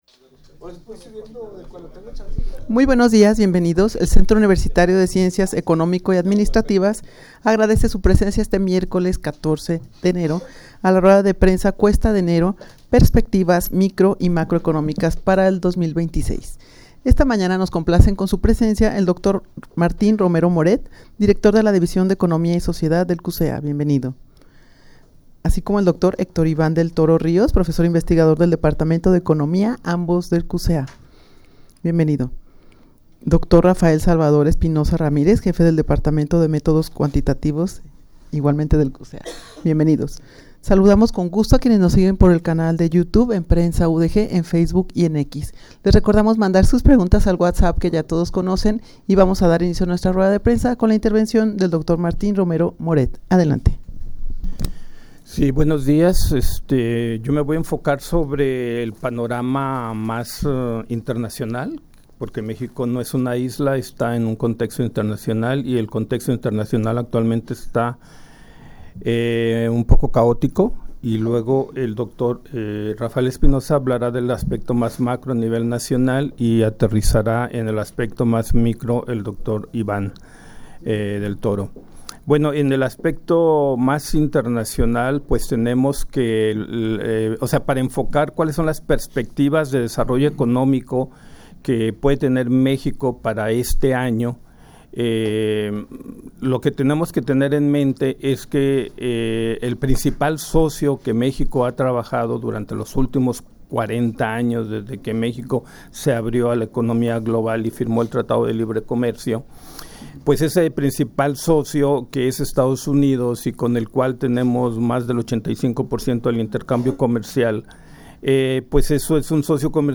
Audio de la Rueda de Prensa
rueda-de-prensa-cuesta-de-enero-perspectivas-micro-y-macro-economicas-para-el-2026.mp3